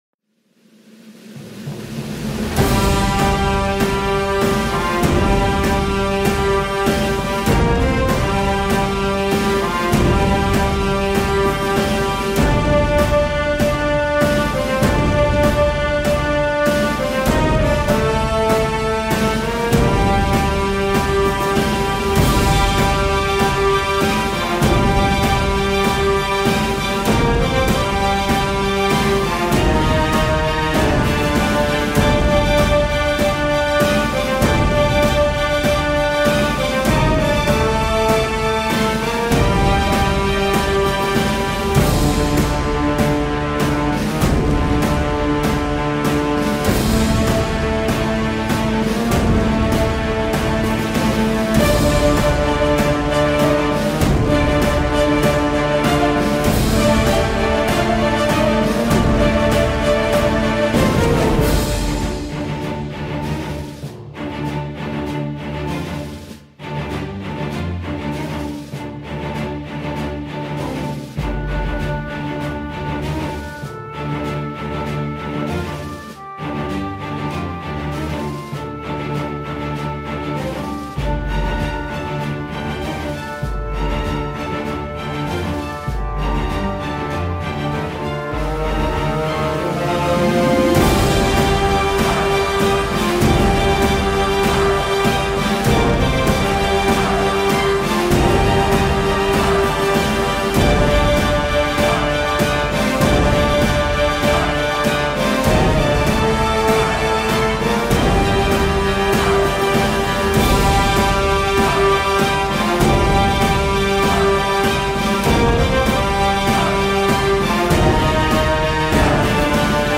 Genre: March Orchestral